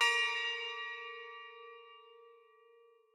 bell1_7.ogg